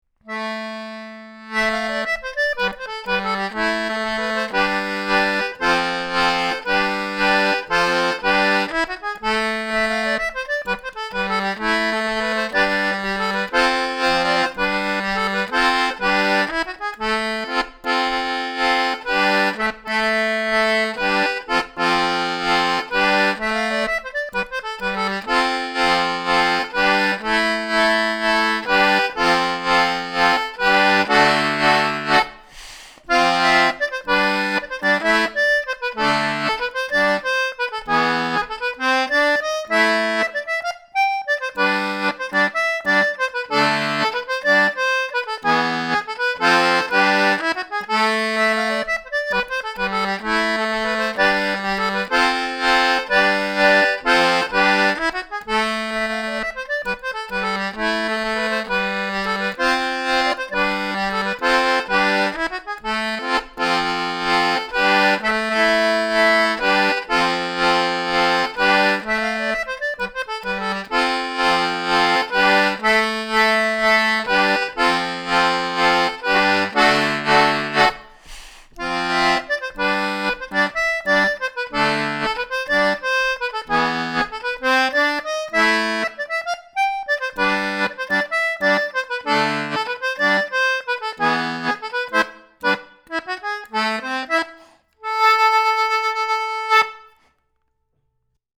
Review of Wolverton C/G and G/D Anglo Concertinas
The tone does reveal its accordion reeds but as you can hear from the recordings it is still very acceptable.